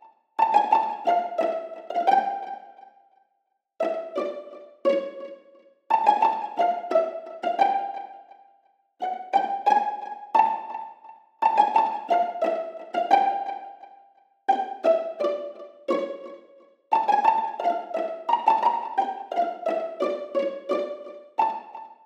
SH4-MeloPizz1_87bpm_Am.wav